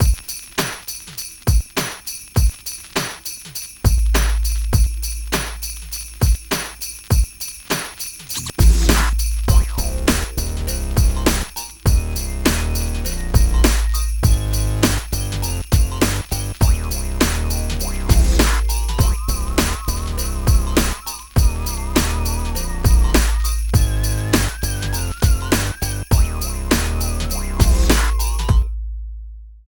13 LOOP   -L.wav